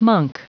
Prononciation du mot monk en anglais (fichier audio)
Prononciation du mot : monk